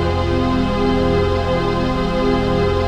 CHRDPAD006-LR.wav